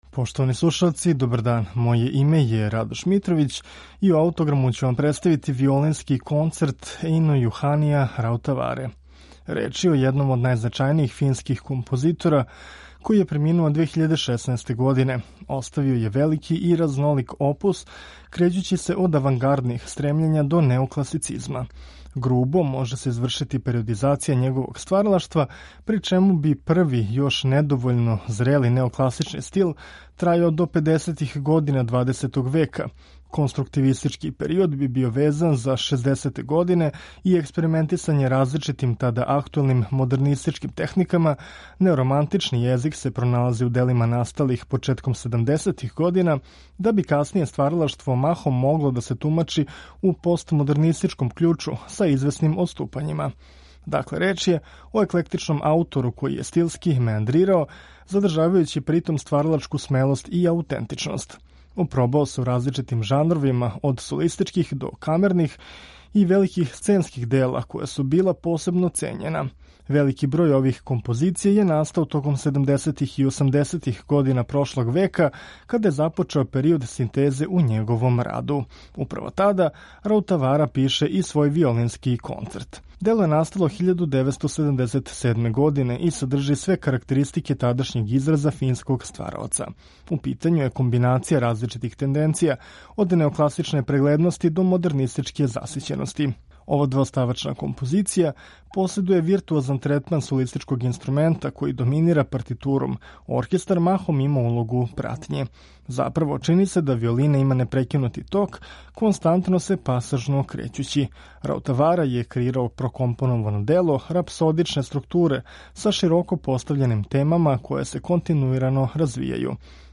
виолиниста